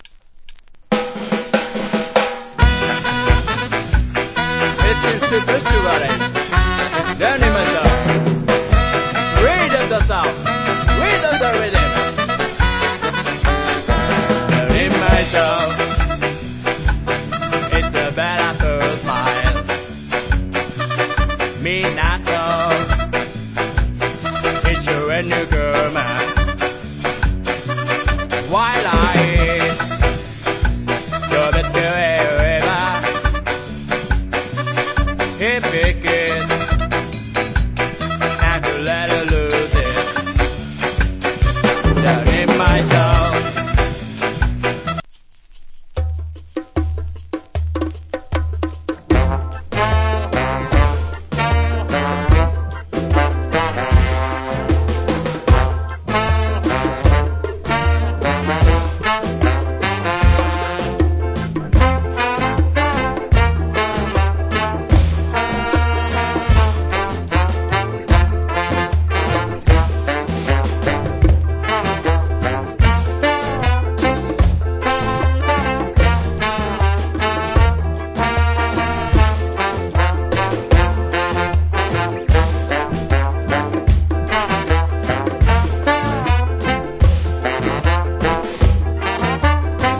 コメント Cool Ska Vocal. Japanese Ska Band. / Nice Inst.